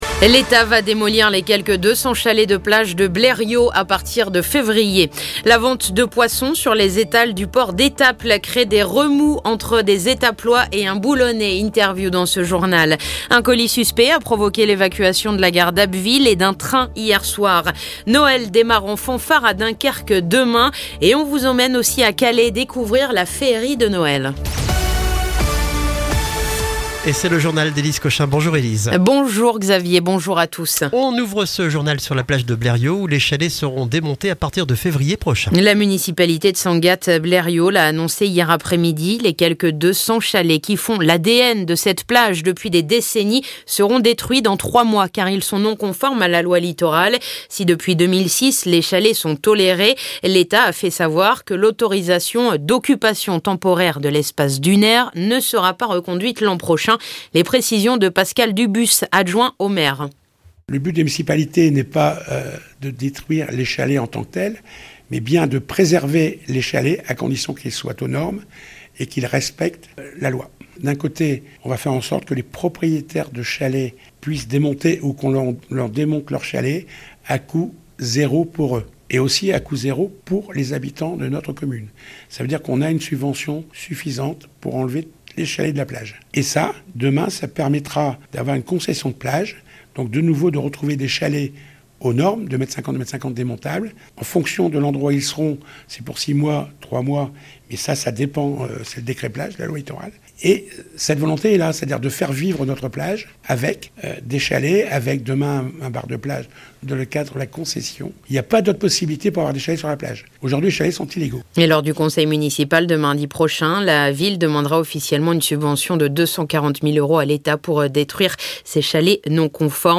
Le journal du vendredi 11 décembre